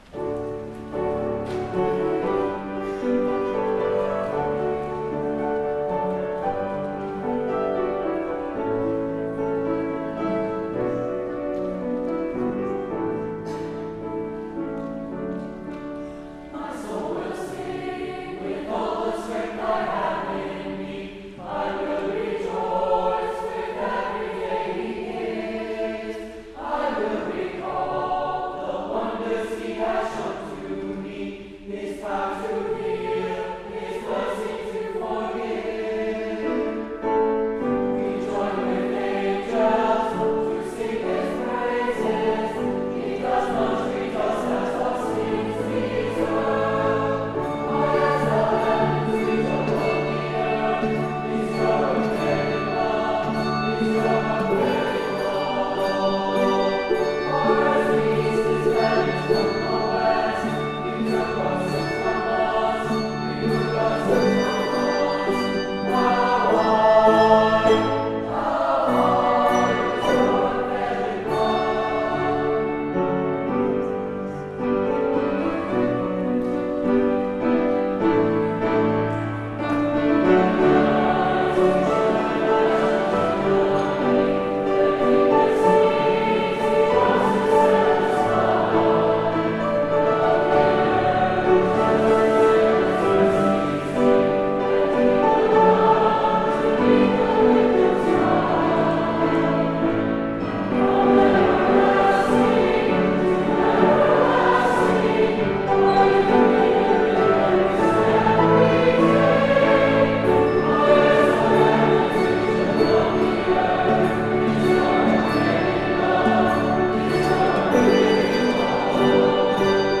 Ascension Hymn Festival, May 10, 2018
Adult Choir